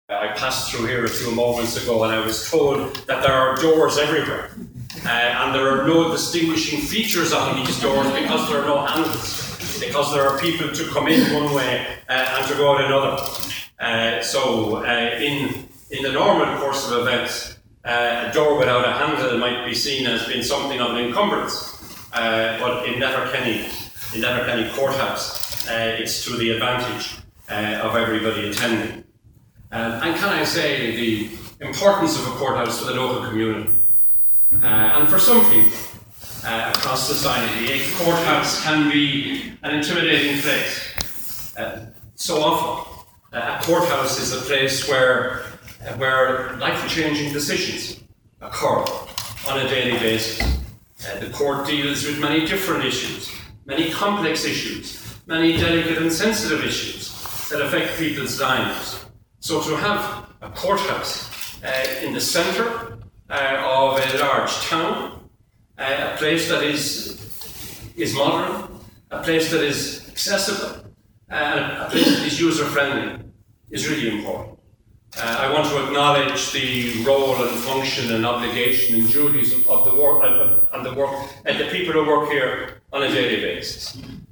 Speaking today at the official opening was Minister Flanagan: